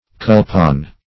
Search Result for " culpon" : The Collaborative International Dictionary of English v.0.48: Culpon \Cul"pon\ (k[u^]l"p[o^]n), n. [See Coupon .]
culpon.mp3